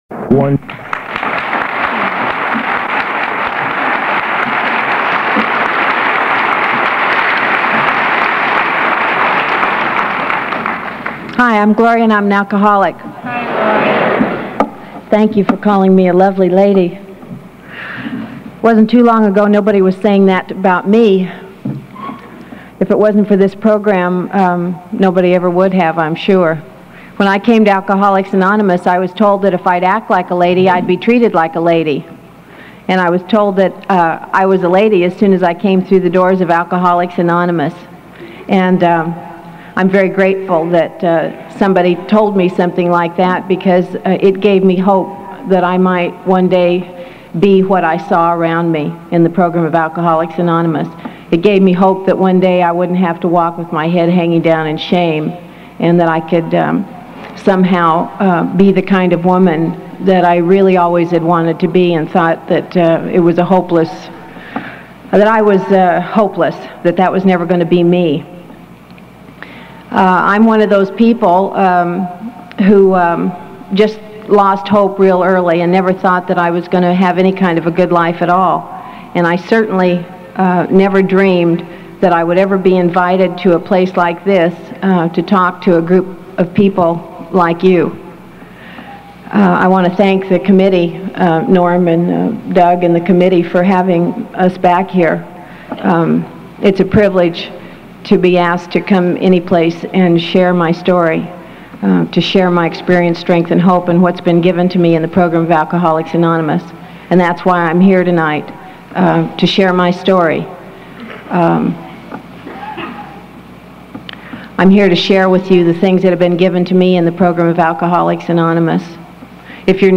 Me A Drinking Problem – Women AA Speakers